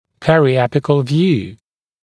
[ˌperɪ’æpɪkl vjuː] [ˌпэри’эпикл вйу:] периапикальный снимок, периапикальная проекция (также peri-apical view)